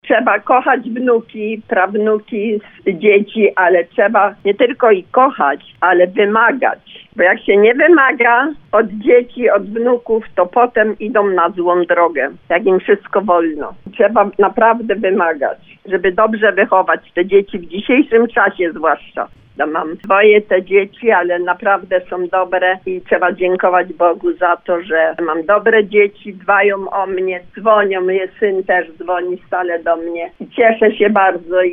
Słuchaczka nie kryła zaskoczenia i wzruszenia, podzieliła się też receptą na skuteczne macierzyństwo.